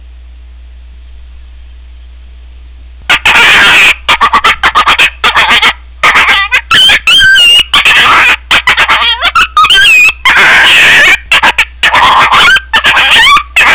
Coyote Pup In Distress
yotepup.wav